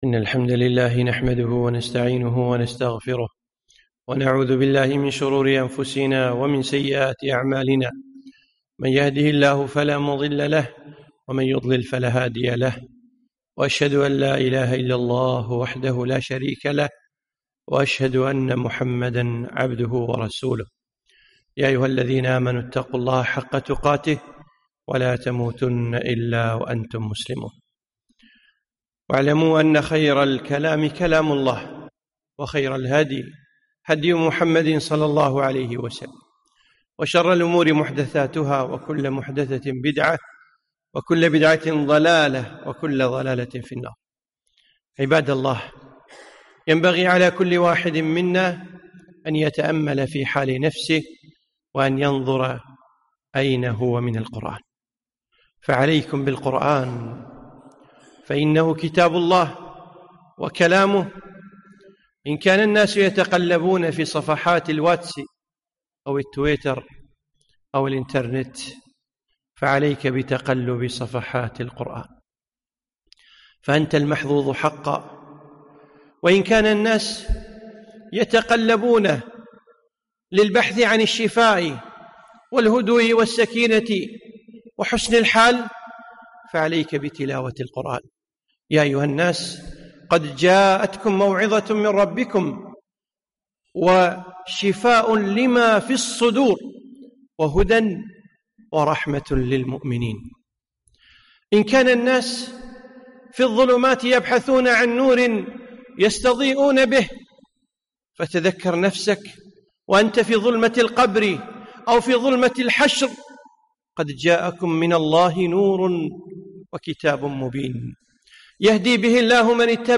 خطبة - عليكم بالقرآن